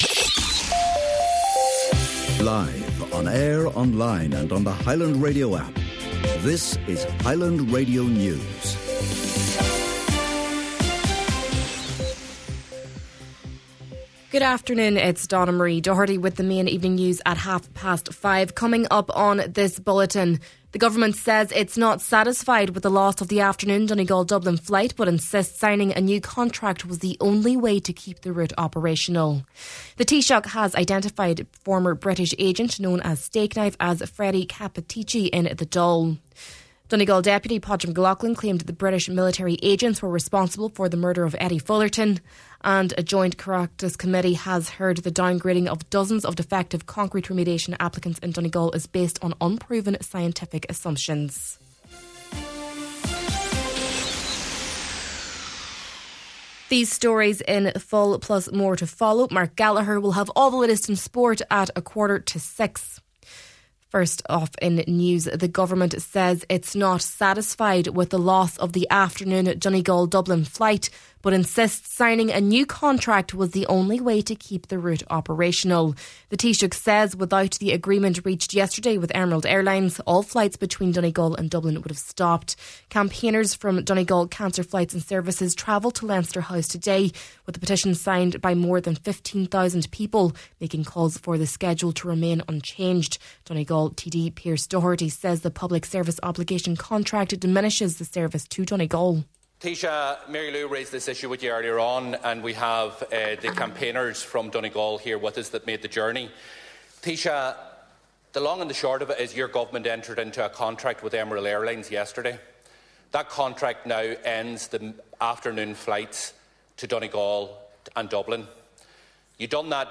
Main Evening News, Sport and Obituary Notices – Wednesday, February 11th